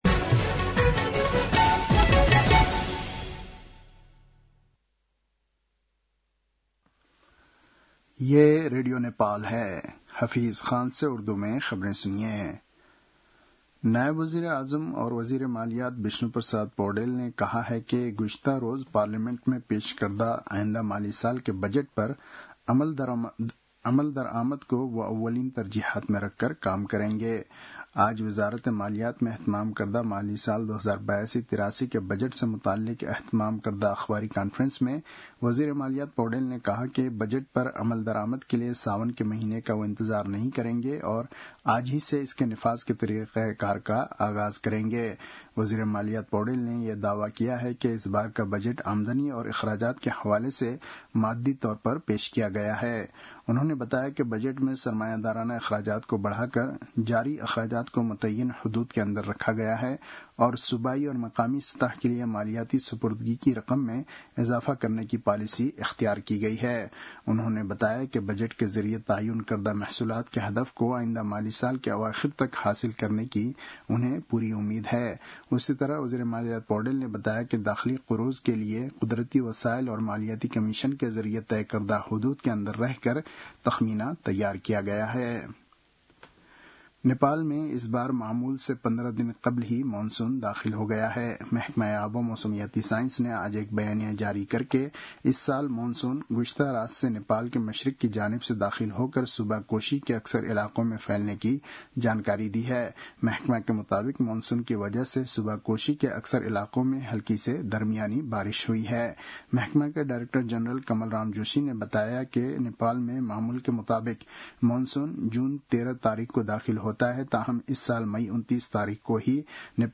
उर्दु भाषामा समाचार : १६ जेठ , २०८२
Urdu-news-2-16.mp3